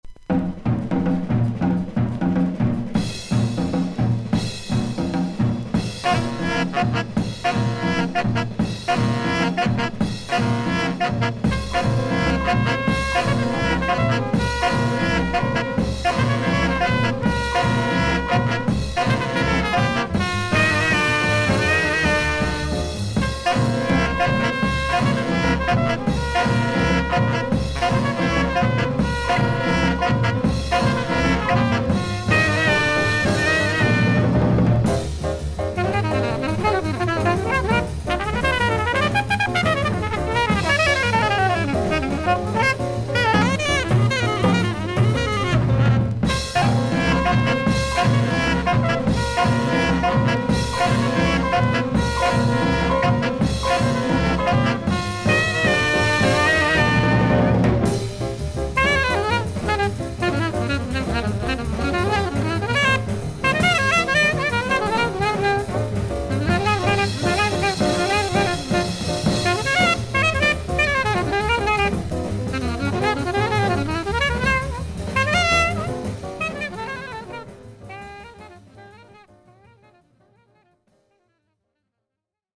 少々サーフィス・ノイズあり。クリアな音です。パチノイズは感じませんでした。
ジャズ・トランペッター。